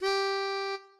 melodica_g.ogg